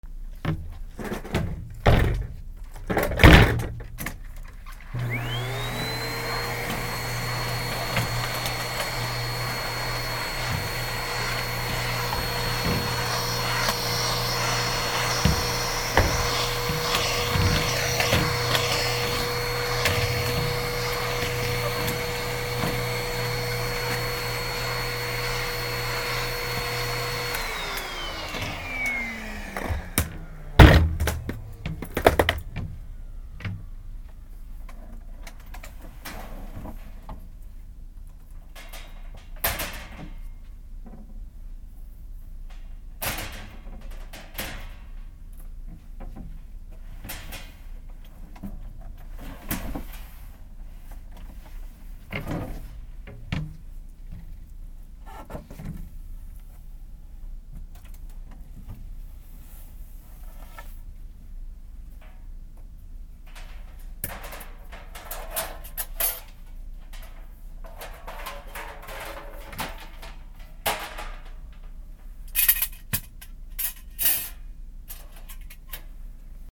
/ M｜他分類 / L10 ｜電化製品・機械
掃除機
『キシュィーン ガタンゴトン』